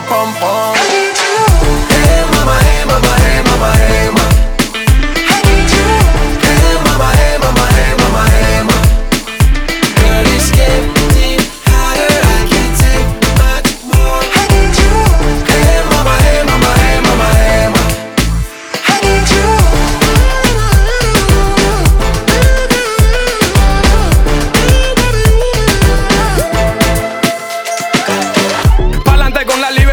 • Latino